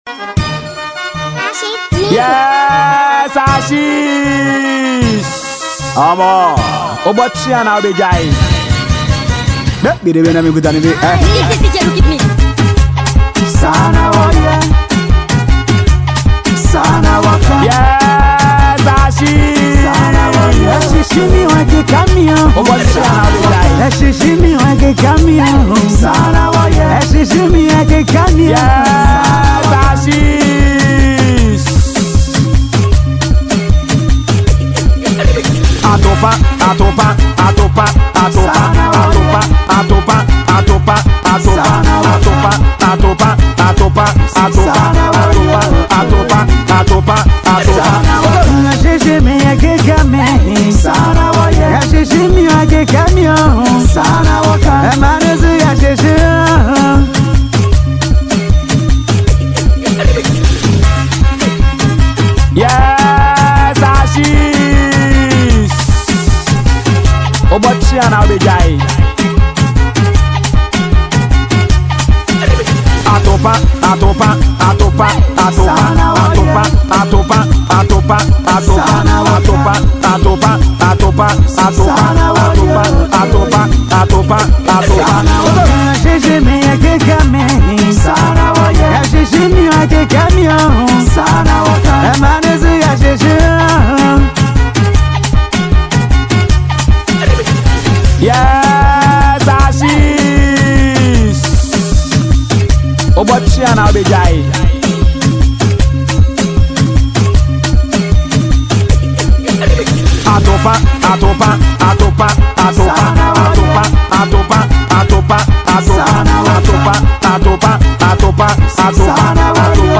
a well-known highlife artist from Ghana